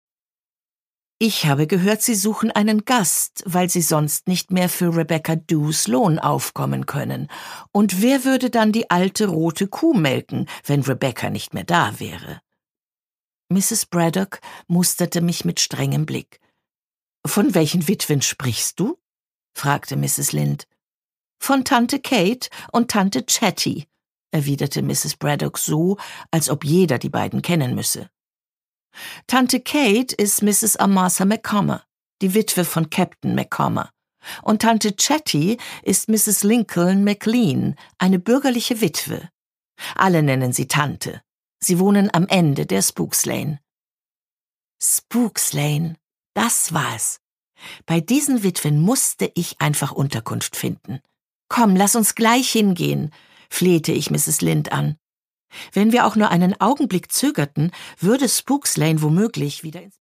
Produkttyp: Hörbuch-Download
Gelesen von: Eva Mattes
Wie schon bei ihren beliebten Lesungen der Jane-Austen-Romane entführt sie mit warmem Timbre in eine unvergessene Welt.